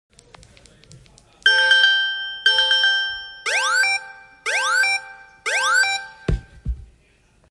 Android 12 emergency SOS sound effects free download
Android 12 emergency SOS